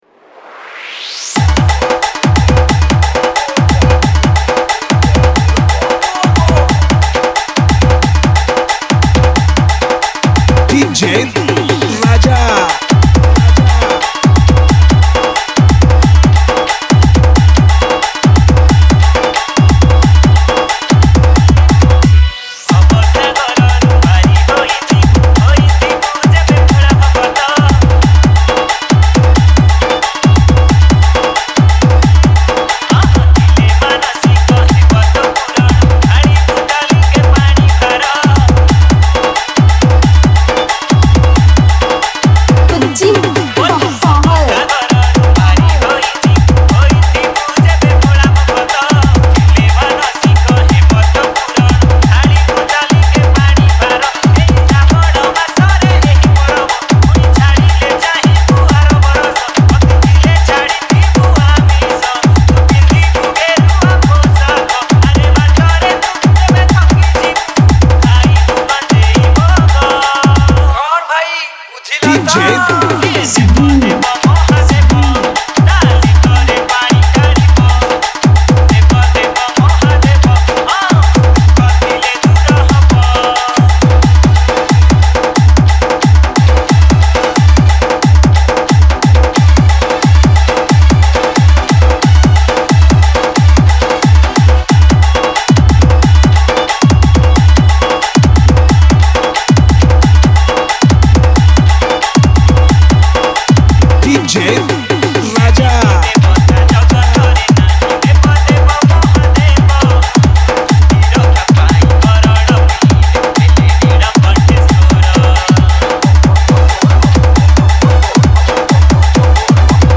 Category : Bolbum Special Dj Song